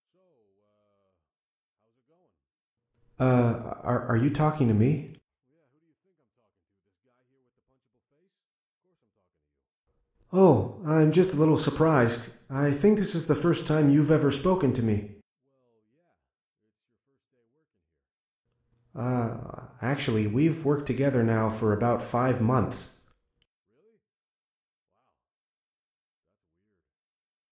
Conversation1b.ogg